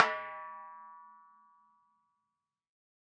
Timbale Zion.wav